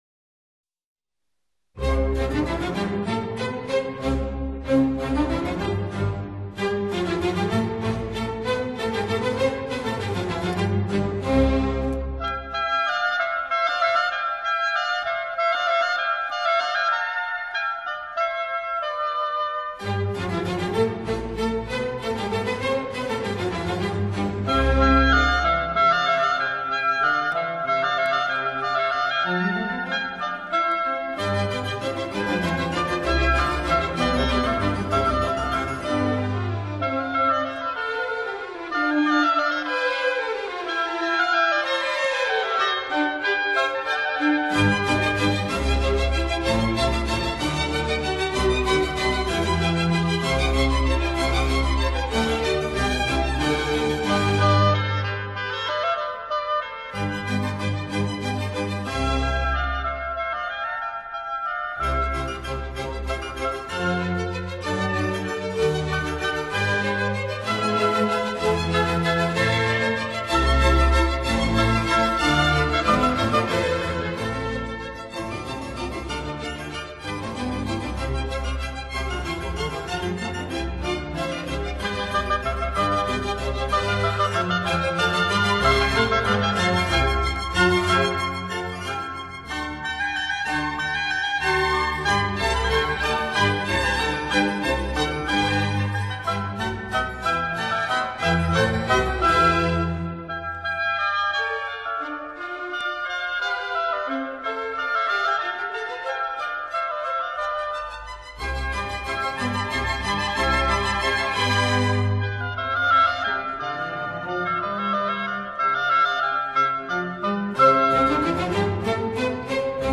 for 2 Oboes, Strings, and Continuo